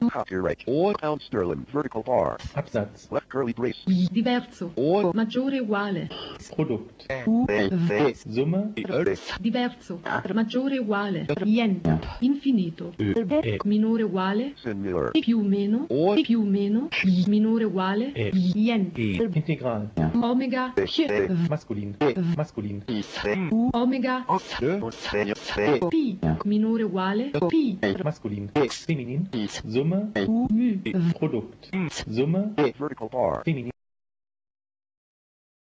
Fashionshow at Art Cologne '97, Gallery Beckers Camera/Sound